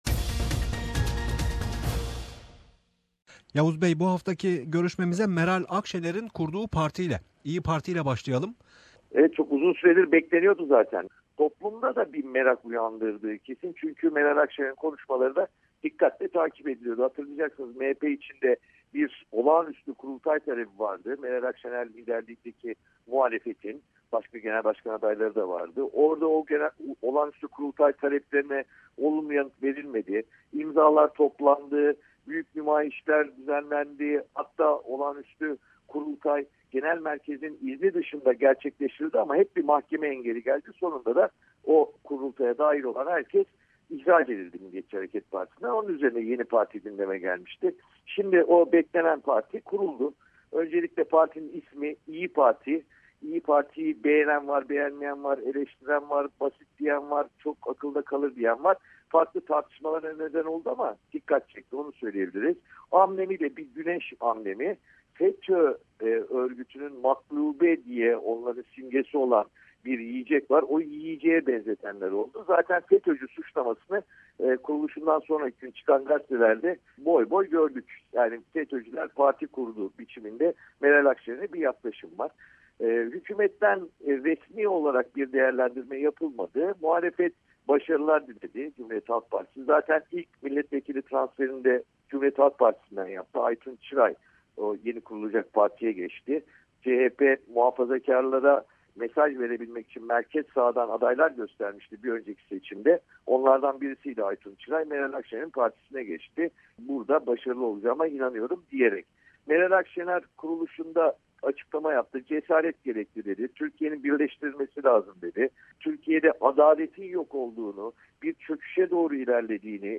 Gazeteci Yavuz Oğhan yeni kurulan İyi Parti'yi, mahkemelerde serbest bırakılan gazeteci ve aydınlar ile Irak'taki gelişmeleri değerlendirdi.